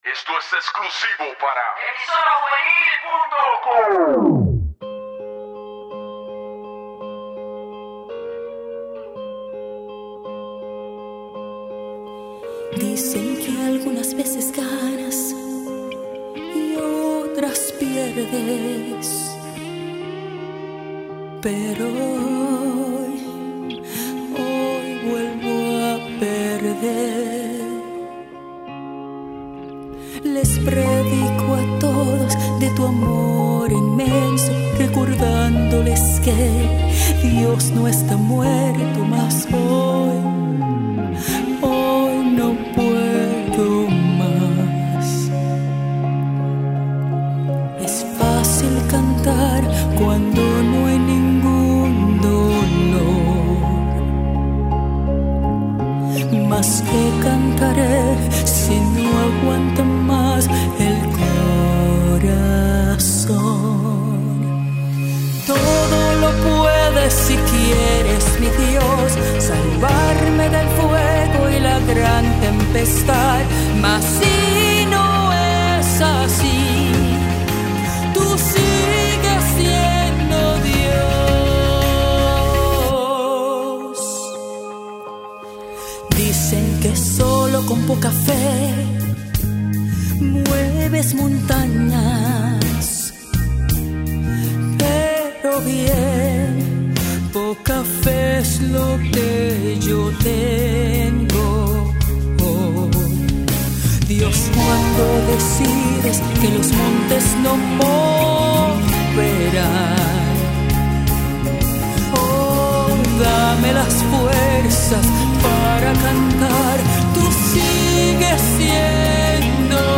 Musica Cristiana
Este sencillo promocional de música cristiana